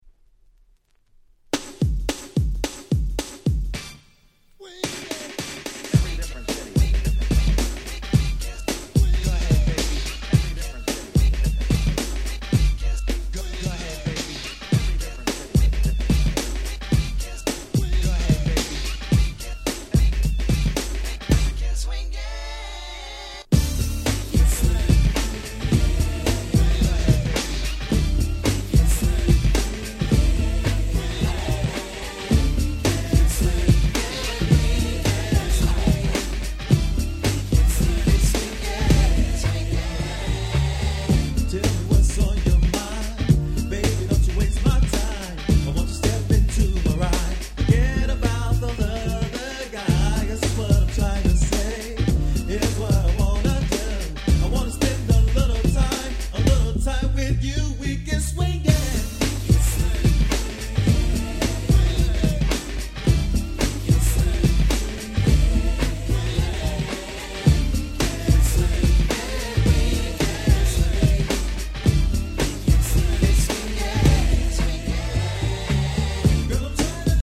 ですので音圧バッチリなこちらのWhite盤をPlay用にオススメいたします！